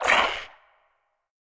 File:Sfx creature triops flinch 01.ogg - Subnautica Wiki
Sfx_creature_triops_flinch_01.ogg